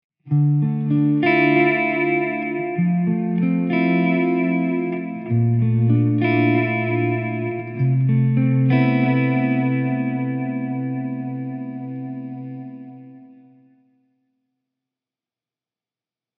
In a simplified definition:  Impulse Responses (IRs) are measurements of acoustic spaces that can be loaded into applications (Like Altiverb or Space Designer) to create different types of reverberations.
Here’s one more example with a clean tone.  It’s subtle but noticable.
And with the same IR as above added: